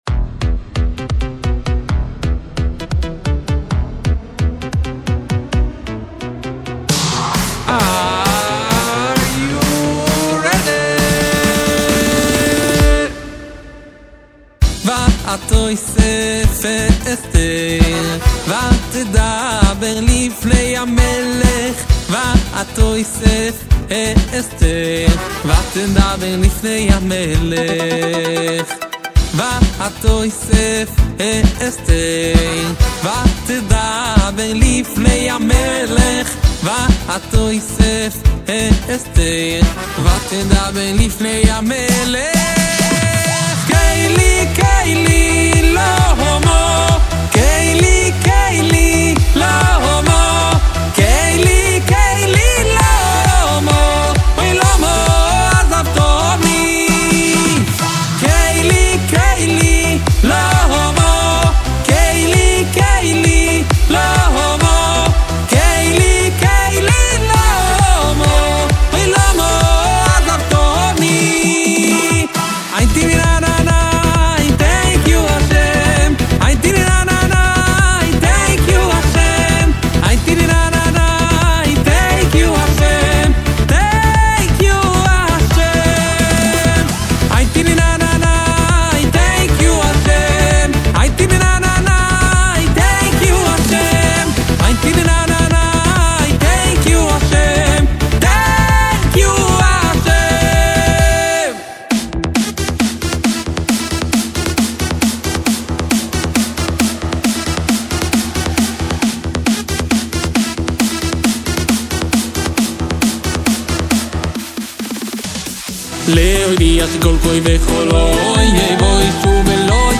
אגב קצת שונה לשמוע מעבר מדאנס לפריילך
פתאום יש מעבר בין דאנס בבילד דראפ לפריילך